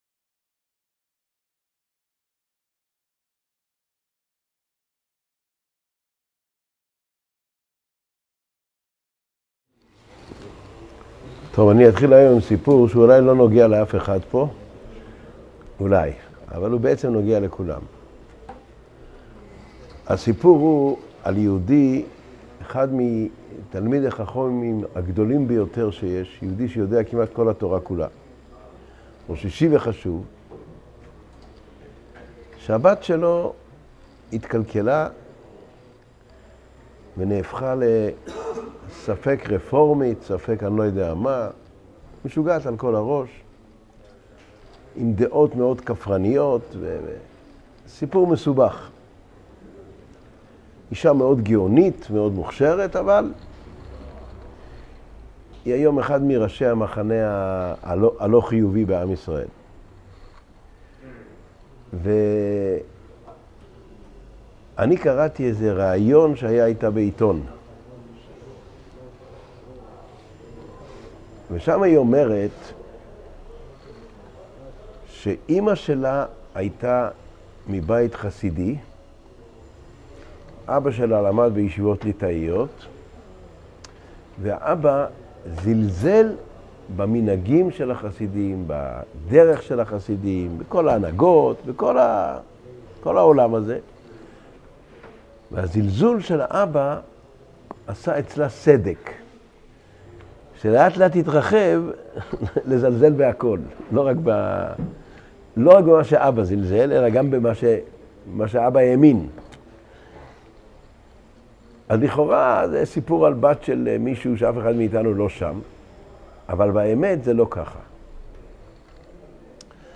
Воспитание детей Урок № 84 Важность эмоциональной связи родителей с детьми Содержание урока